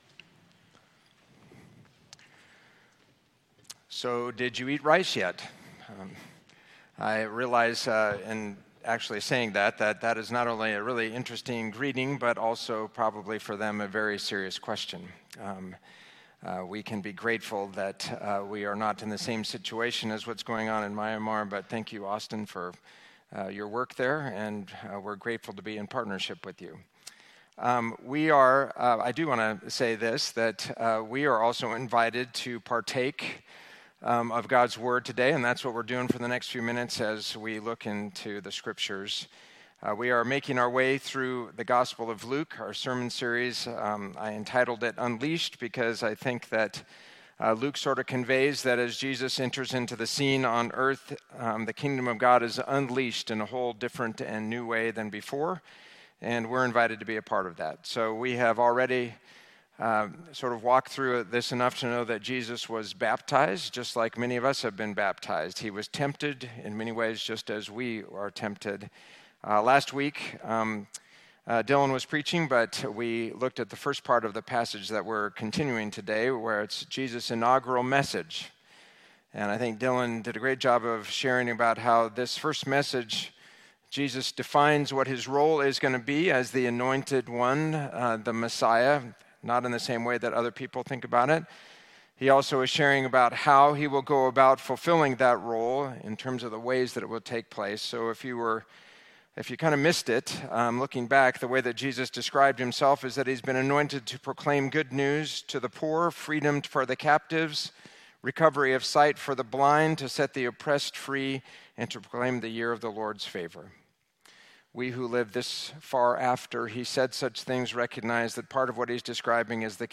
Sermon
at the traditional service on January 26, 2025.